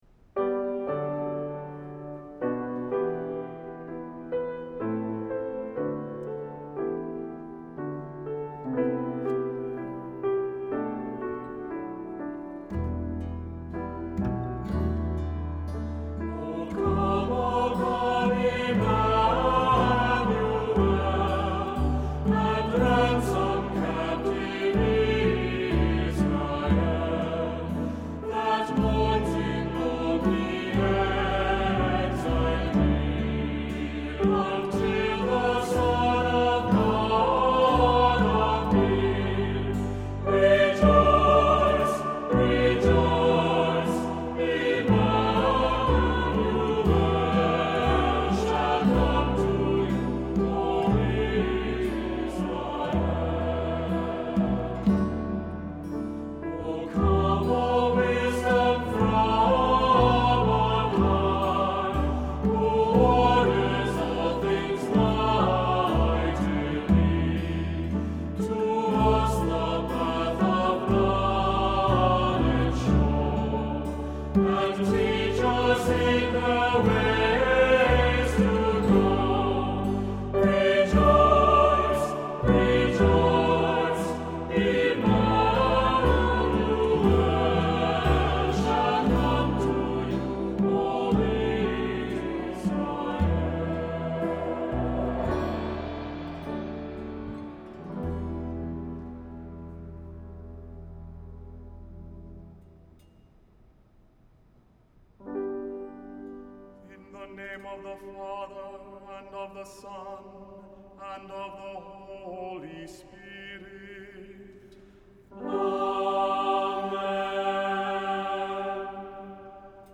Voicing: SATB; Cantor; Priest; Assembly